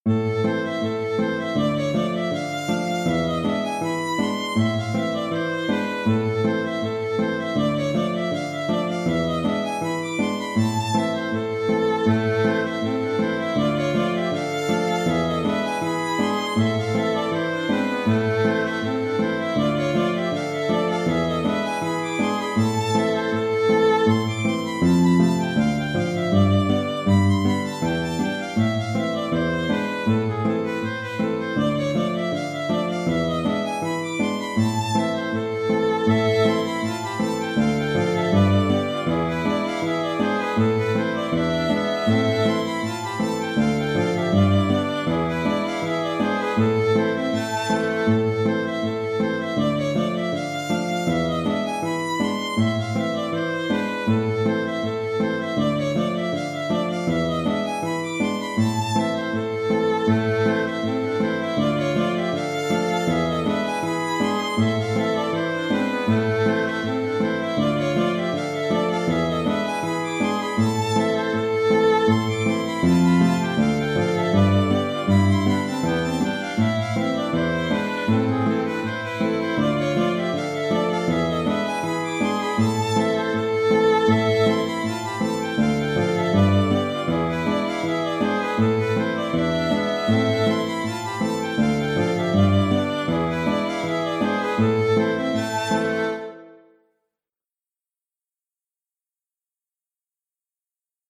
Scottish finlandaise "Pour sortir" (à l'octave) (Scottish) - Musique folk
Version à l'octave du thème précédent, pour faire variation. Le contrechant en est un peu différent (plus simple).